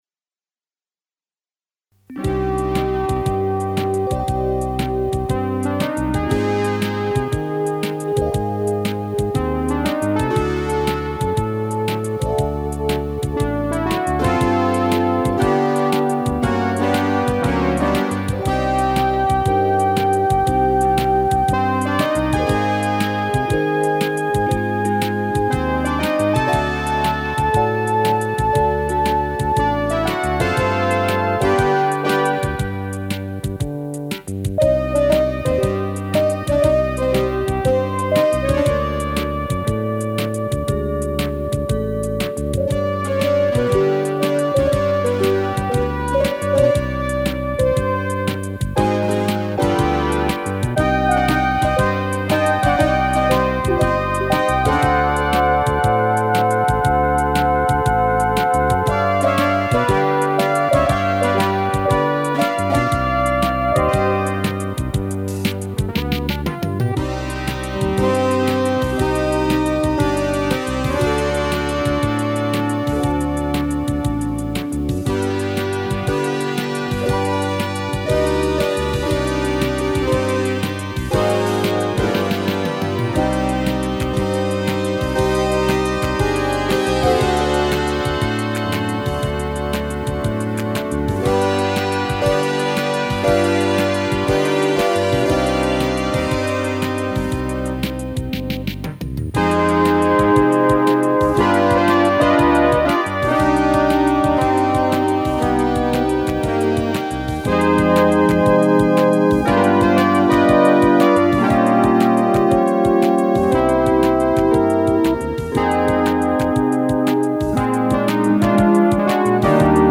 И сделаны эти записи исключительно на синтезаторе !